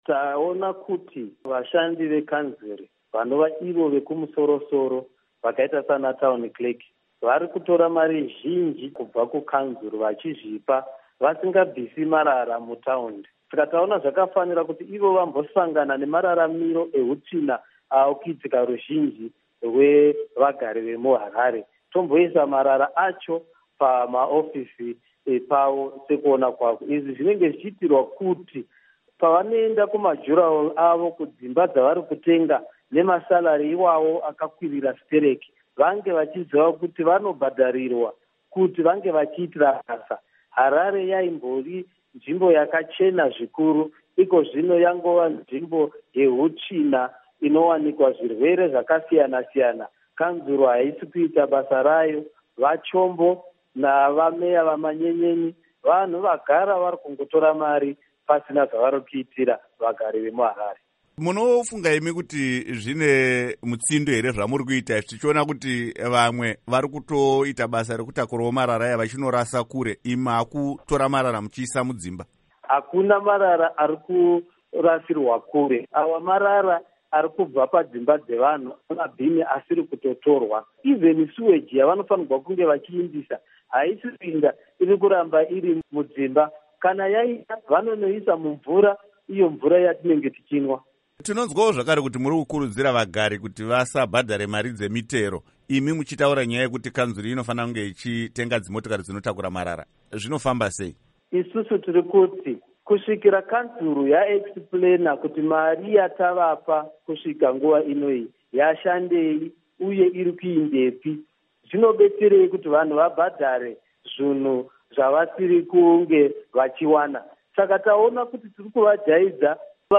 Hurukuro naVaJacob Mafume